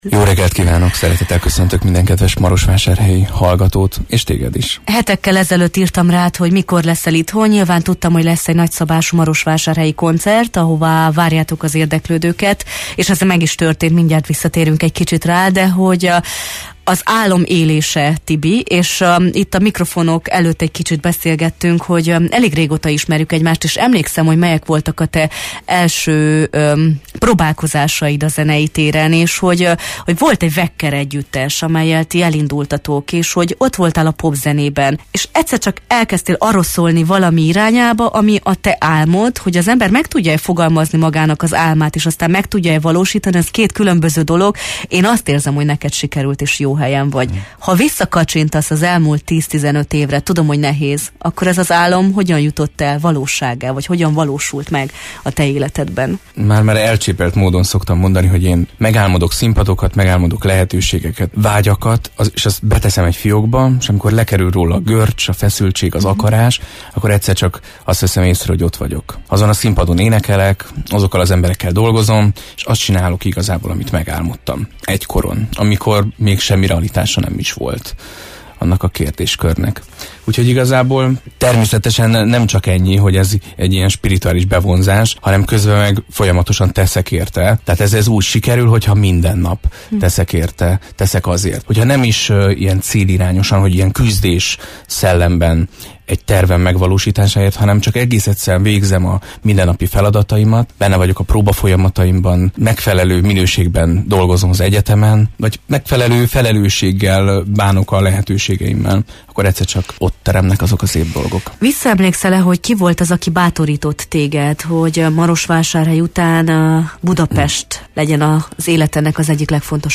Az elmúlt év kihívásairól, szakmai sikereiről, hitről, kitartó munkáról kérdeztük a Jó reggelt, Erdély!-ben: